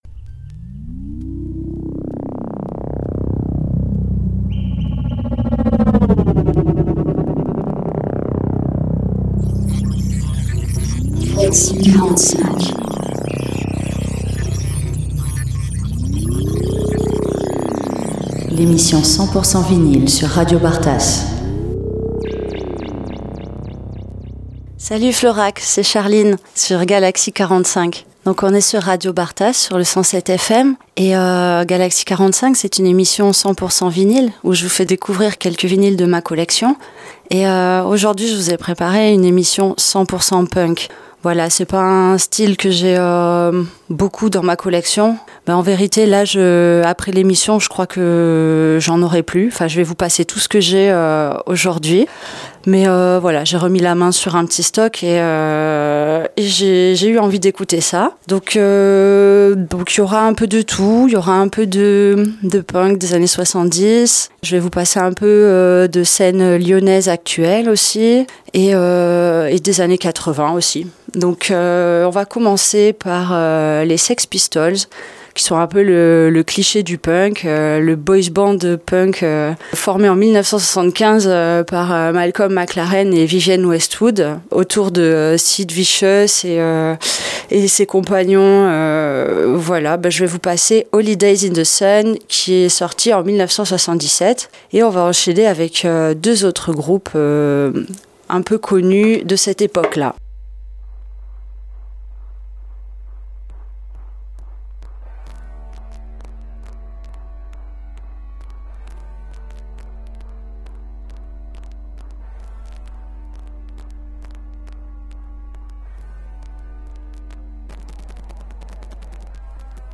8e édition de galaxie 45, votre émission 100% vinyles avec un épisode spécial musique punk !